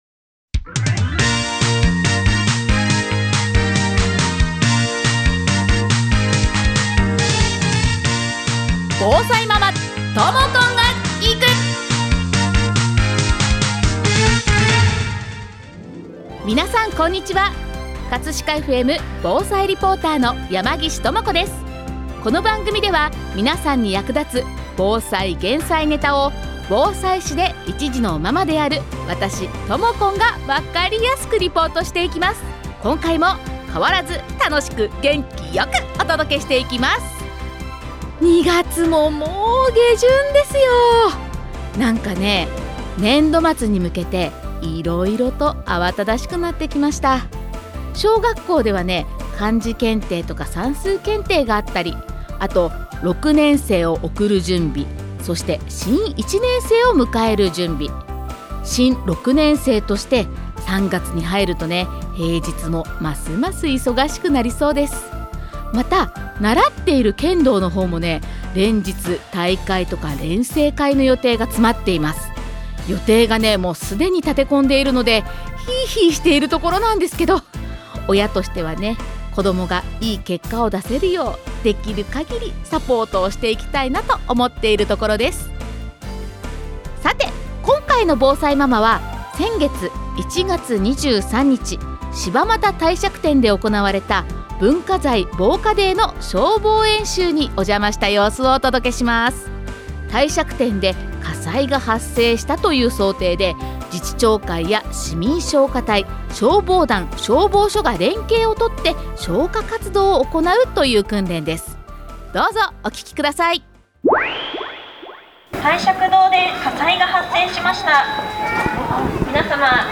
今回は1月23日(金)柴又帝釈天で行われた文化財防火デーに伴う消防演習にお邪魔しました！